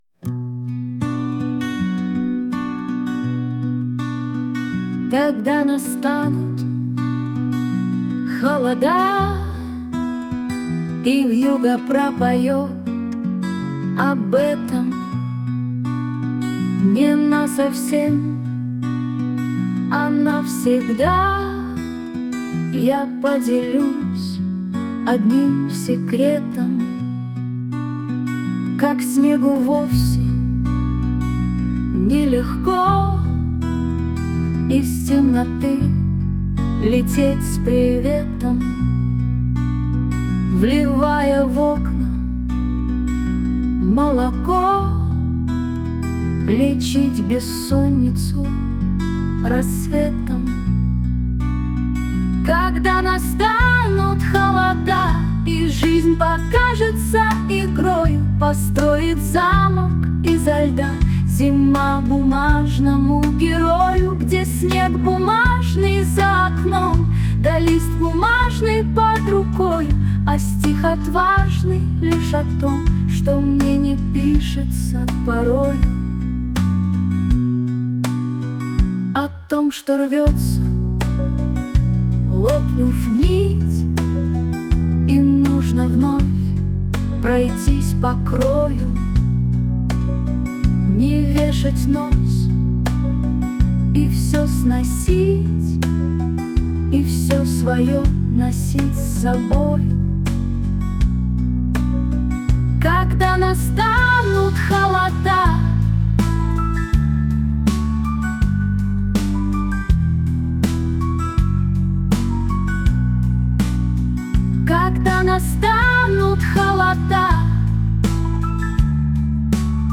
Поёт ИИ.